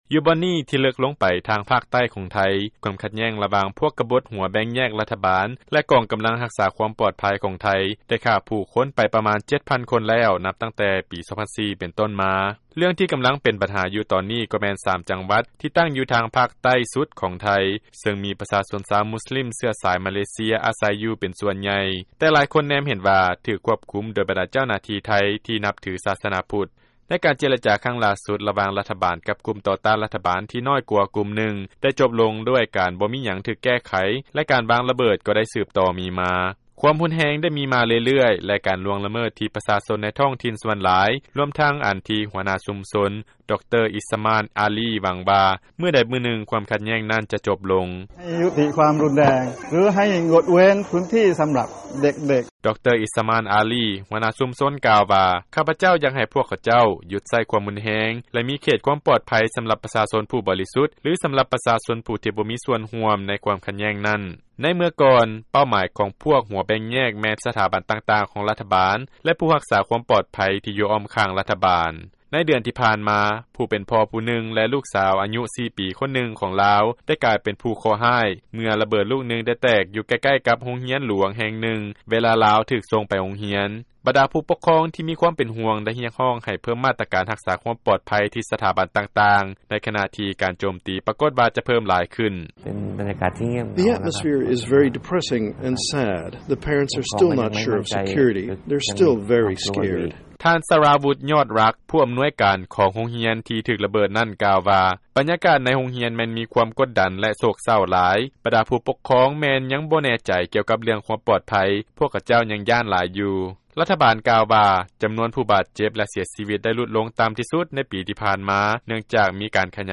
ຟັງລາຍງານ Thailand Southern Conflict